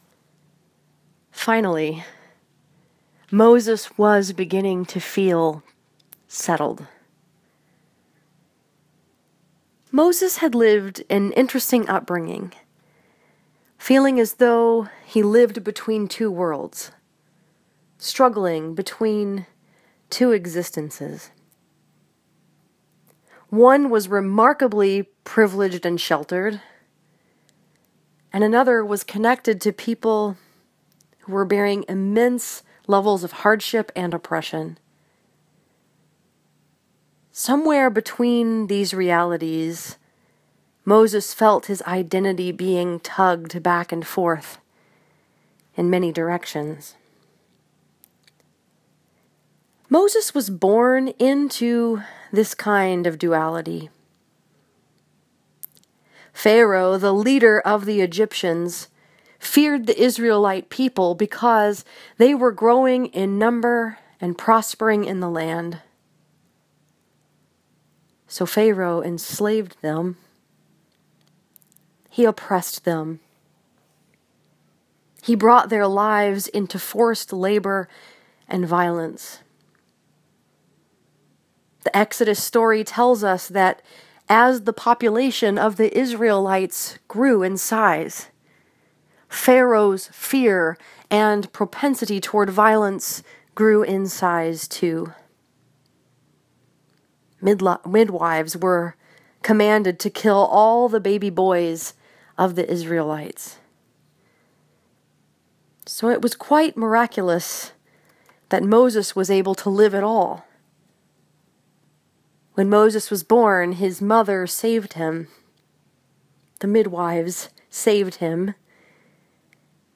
This sermon was preached at Northside Presbyterian Church in Ann Arbor, Michigan and is based on Exodus 3:1-15.